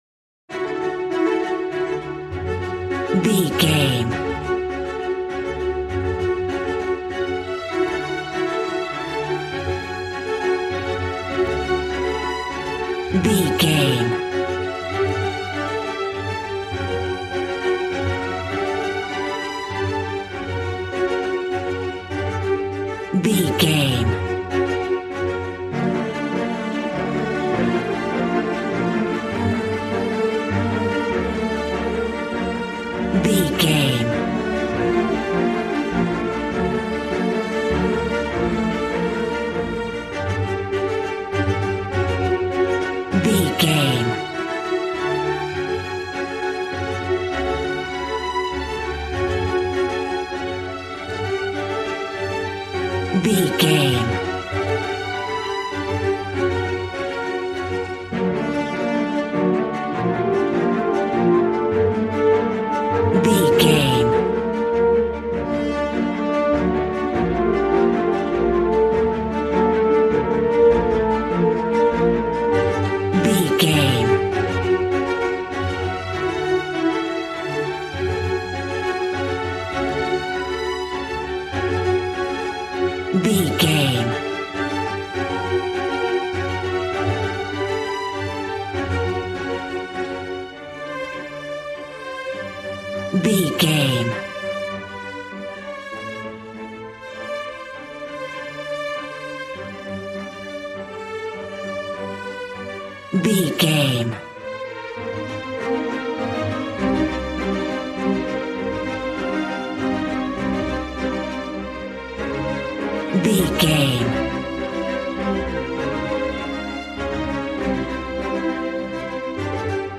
Ionian/Major
regal
cello
violin
strings